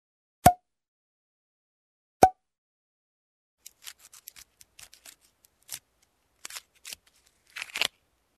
Звуки ресторана
Вытягивают пробку из бутылки